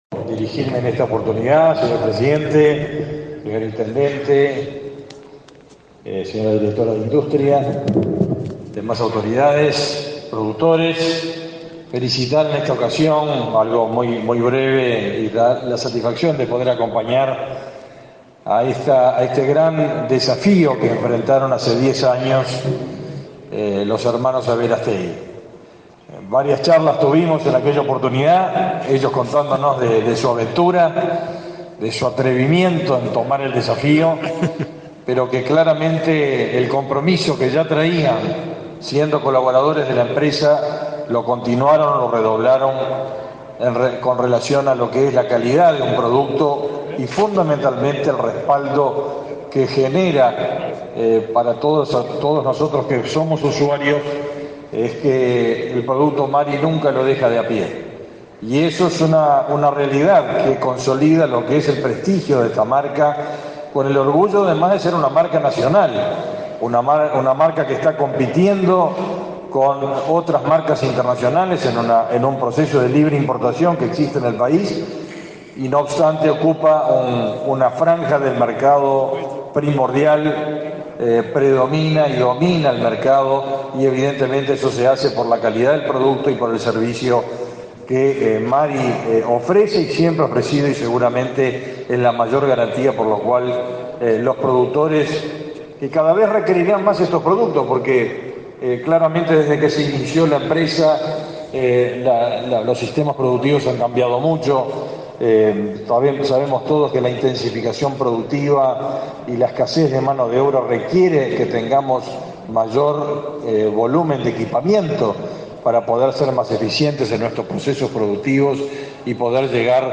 Palabras del ministro de Ganadería, Fernando Mattos, y la directora de Industrias, Susana Pecoy
Con la presencia del presidente de la República, Luis Lacalle Pou, se realizó, este 20 de octubre, el acto de celebración del 60.° aniversario de la empresa Mary SRL, en la localidad de Santa Catalina. Disertaron en la actividad el ministro de Ganadería, Agricultura y Pesca, Fernando Mattos, y la directora nacional de Industrias, Susana Pecoy.